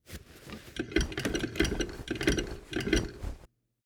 Korba
1576_crank.mp3